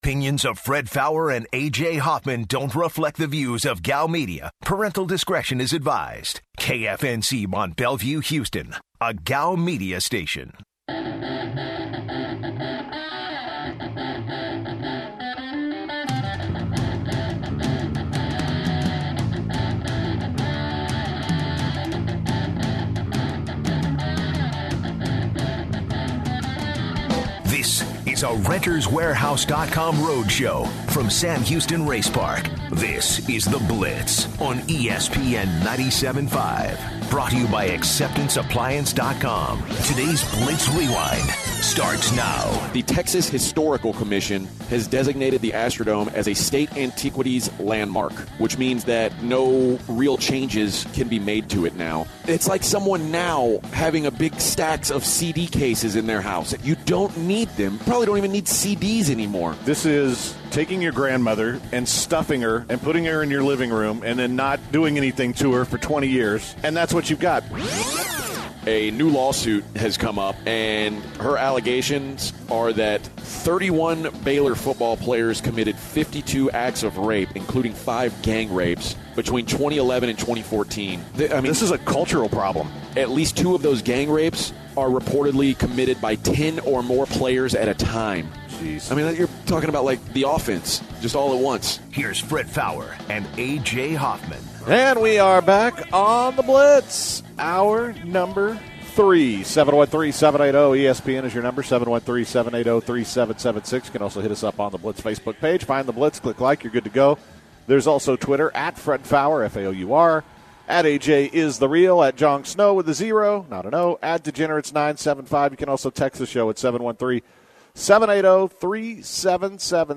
also interviews russian MMA fighter Valentina Shevchenko about her fight against Julianna Pena.